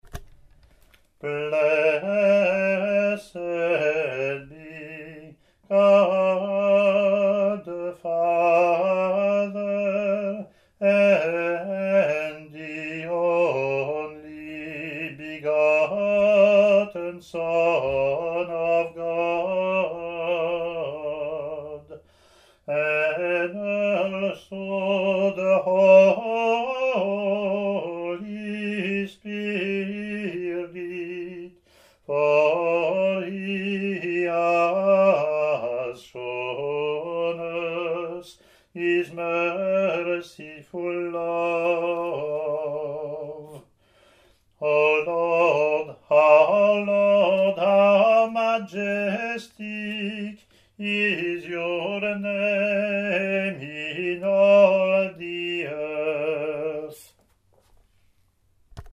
English antiphon – English verseLatin antiphon and verse)